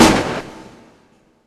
50 Gun Shot.wav